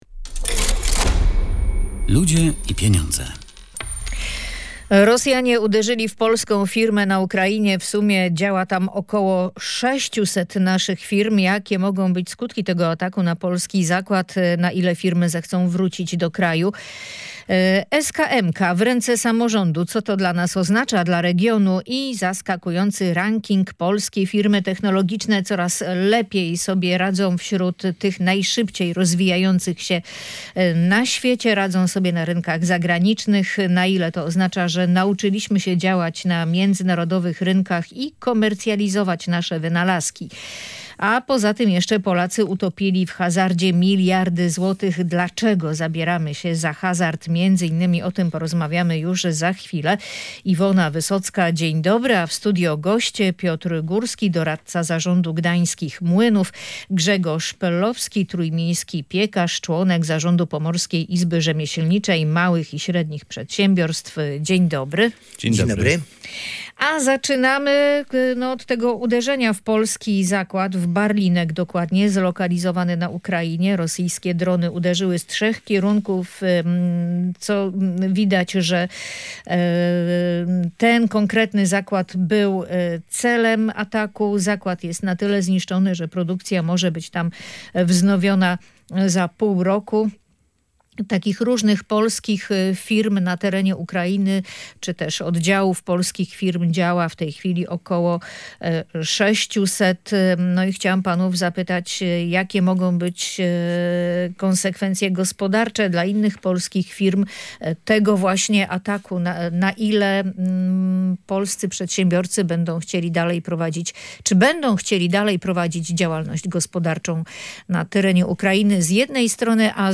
Usamorządowienie SKM będzie łączyć się z wyzwaniami, ale też szansą na szybszy rozwój regionu - mówią goście audycji "Ludzie i